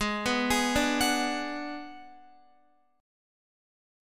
G#m7b5 Chord